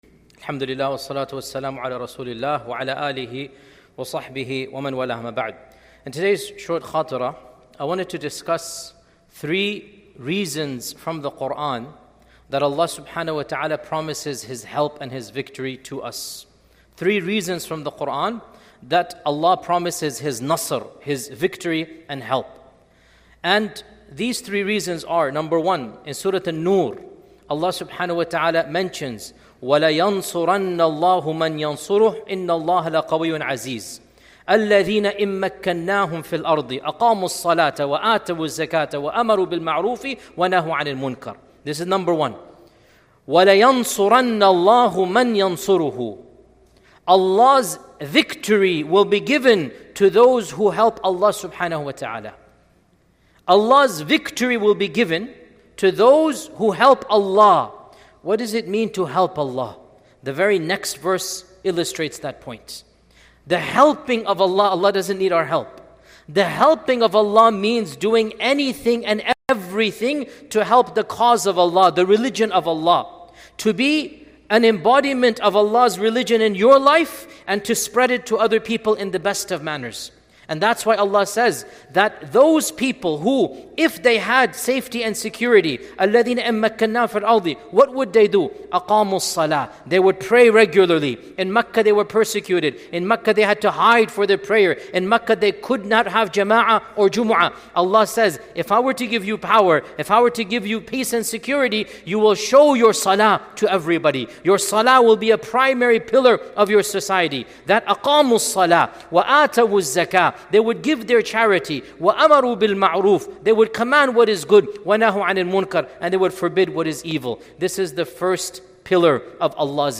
3 Reasons that Allah Promises Victory ｜ Shaykh Dr. Yasir Qadhi ｜ Isha Khatira.mp3